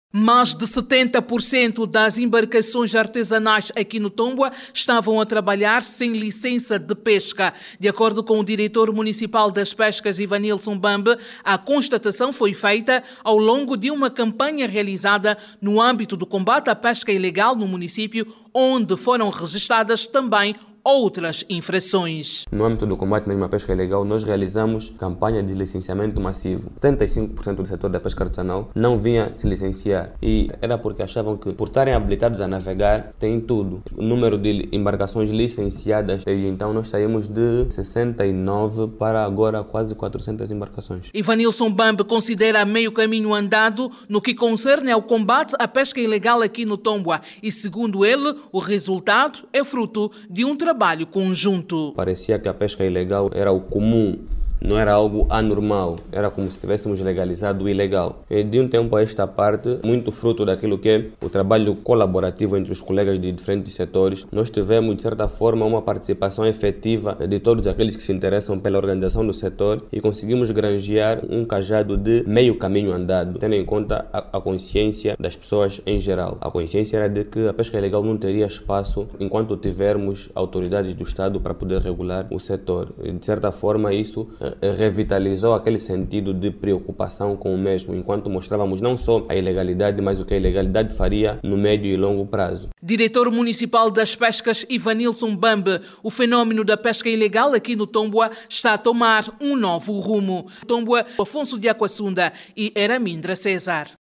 No município do Tômbwa, o Programa de Combate à Pesca Ilegal já permitiu o licenciamento de perto de 400 embarcações dedicadas à pesca artesanal. Jornalista